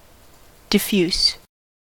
diffuse-adjective: Wikimedia Commons US English Pronunciations
En-us-diffuse-adjective.WAV